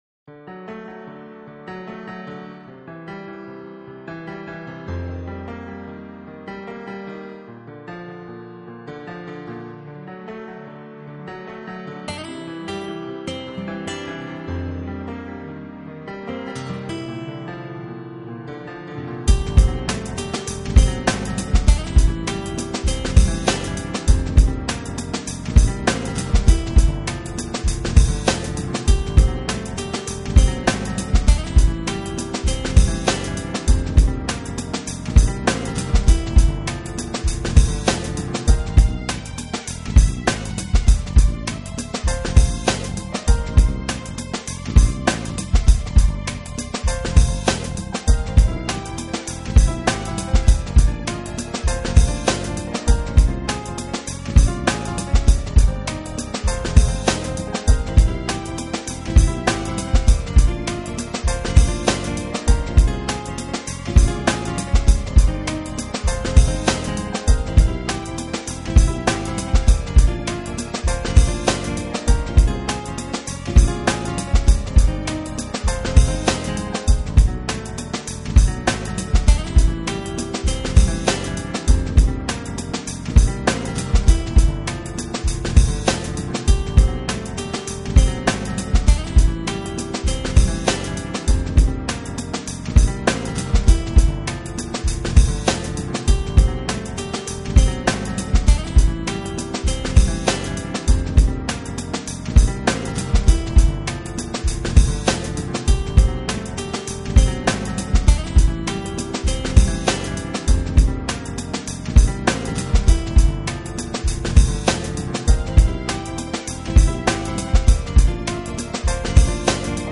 I really like the main piano that starts the track and flows throughout the entire song.
Underground NY style hip hop on the beat, soulful fusion, jazz, guitar, wicked synth sounds.
I particularly like how the guitar and piano share space without crowding each other.
In a way it is a drum machine.